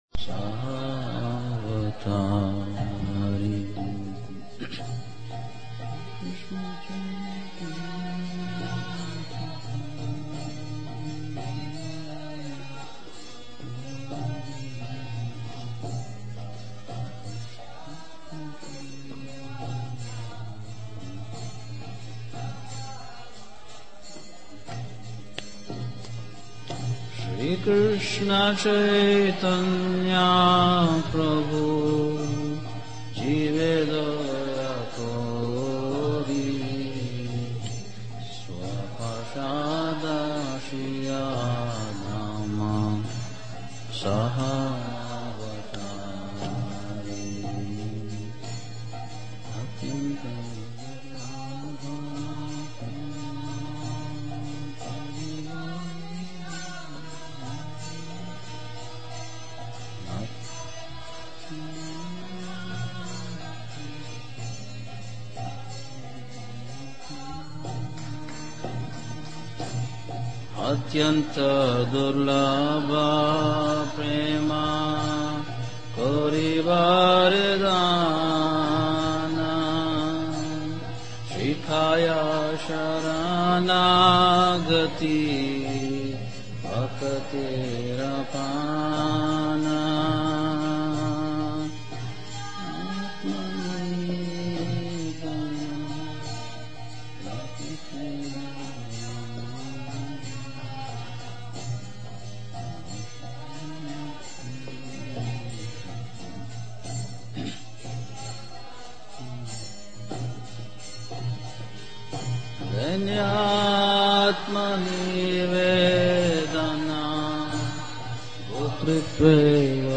Workshop – Self-Acceptance Precedes Self-Improvement and Acceptance of Others